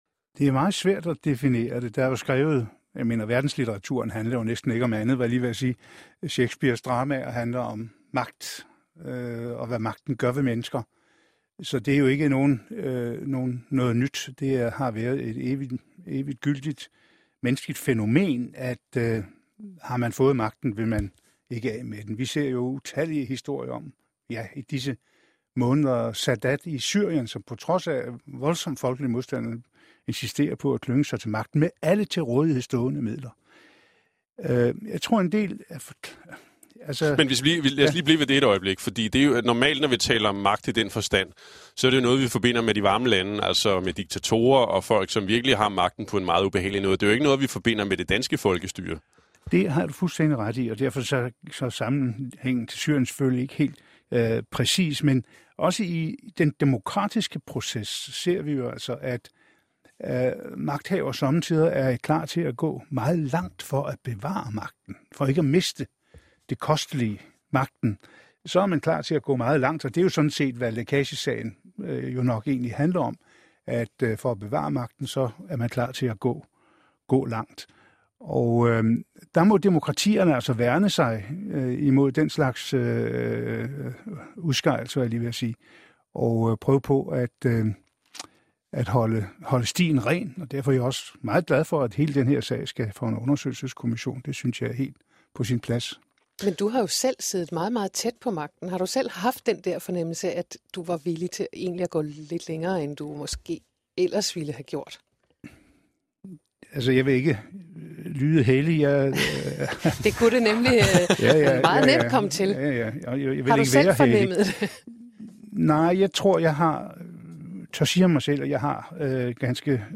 En dag stod tidligere udenrigsminister Niels Helveg Petersen (R) pludselig i studiet og sagde, at han skulle være med i programmet.